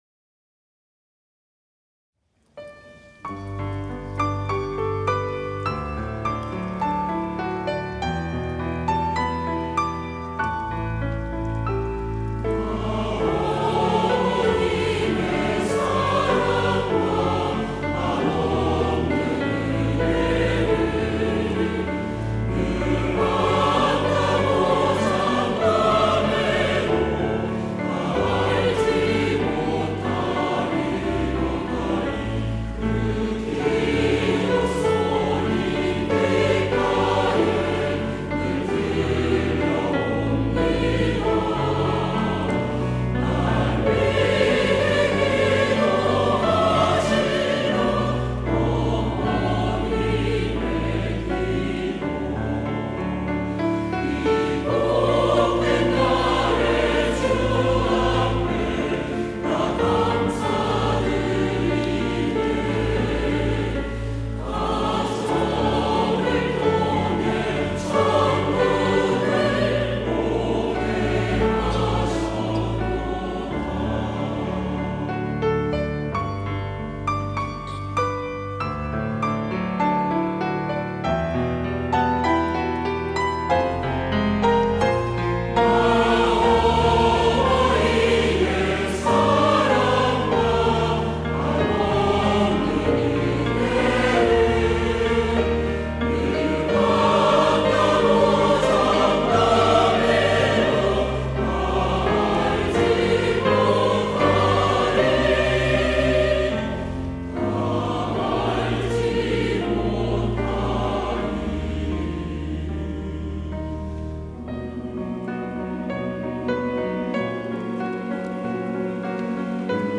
시온(주일1부) - 어머니날을 위한 기도
찬양대